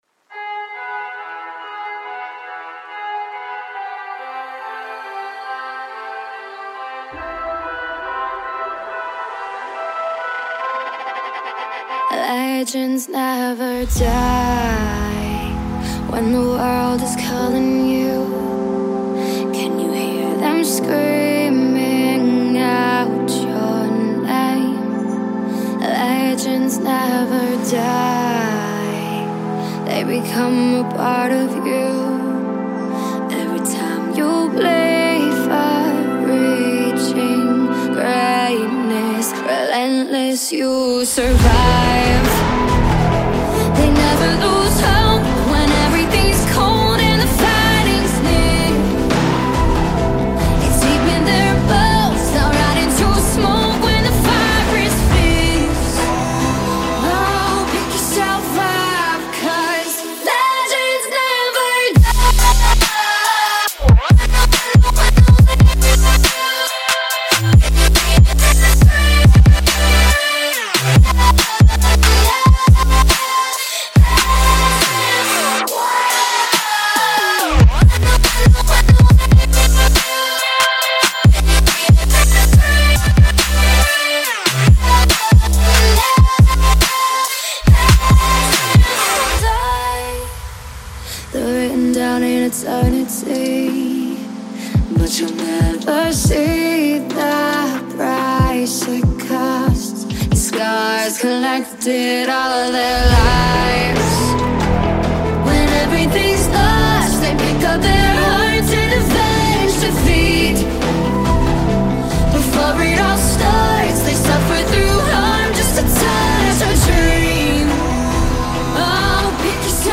Future Bass涉及的是硬音鼓，醒目的人声排练和FULL混合音。